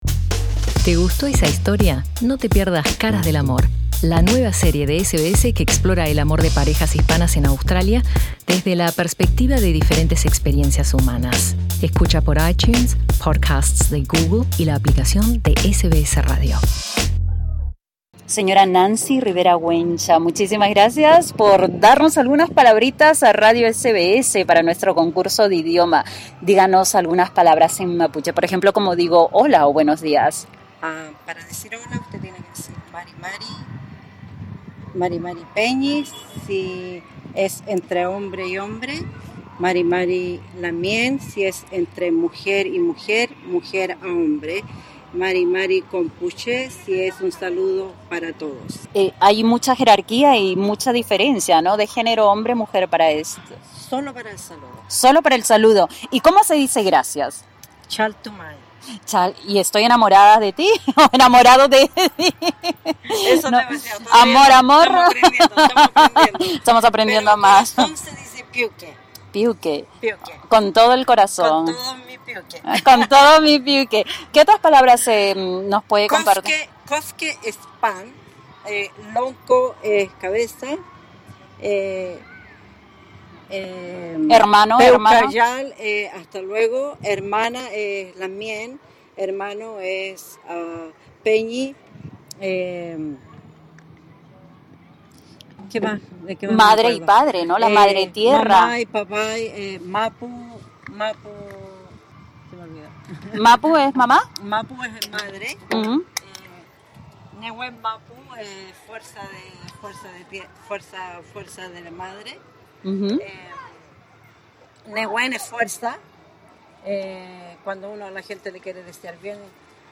En nuestra participación en las celebraciones patrias del club Colo Colo en el Fairfield Showground, en el oeste de Sídney